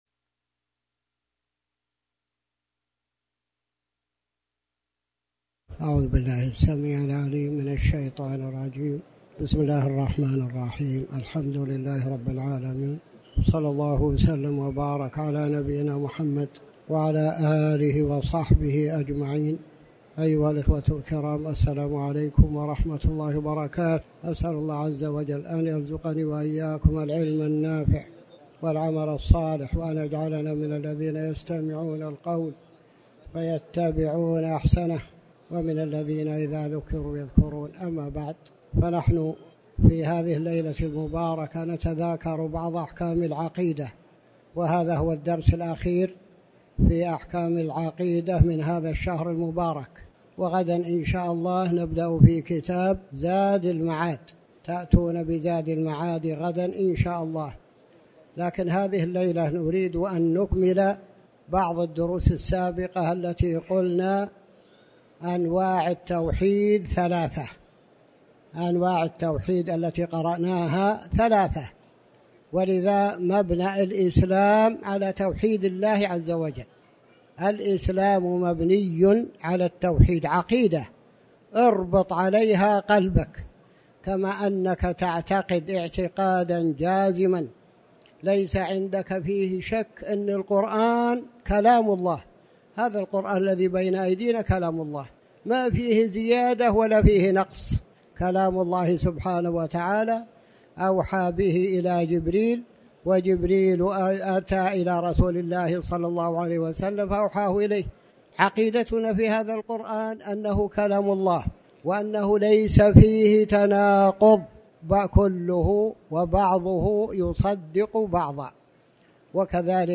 تاريخ النشر ٣٠ ربيع الثاني ١٤٤٠ هـ المكان: المسجد الحرام الشيخ